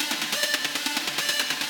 Index of /musicradar/shimmer-and-sparkle-samples/140bpm
SaS_Arp04_140-E.wav